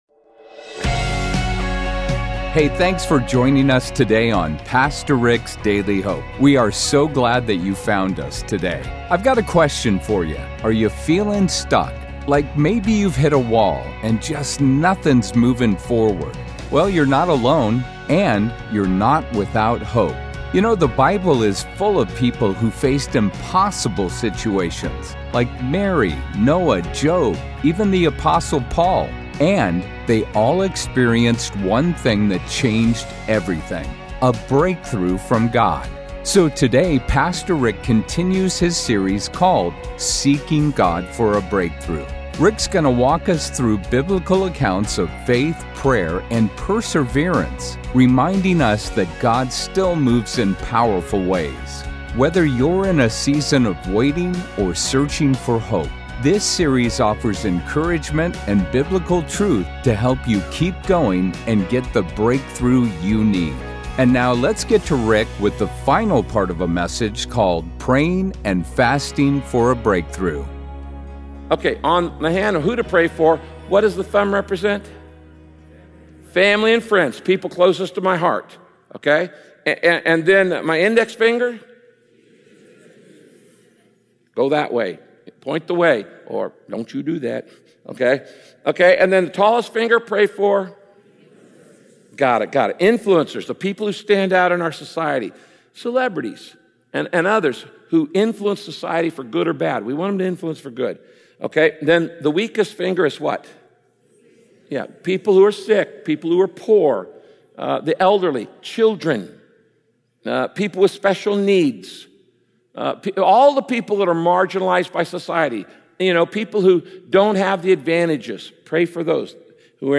Episode Webpage Hosts & Guests Rick Warren Host Information Show Pastor Rick's Daily Hope Frequency Updated daily Published 22 September 2025 at 08:30 UTC Length 25 min Rating Clean